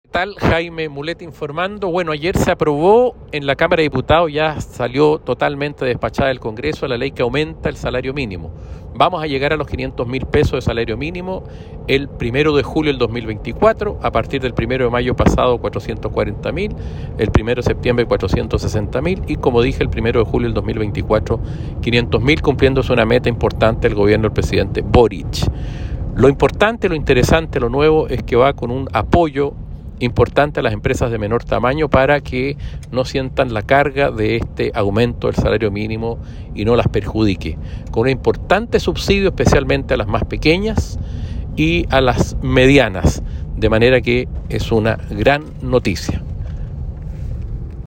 Audio Diputado Jaime Mulet sobre aumento de salario mínimo y apoyo a las pequeñas empresas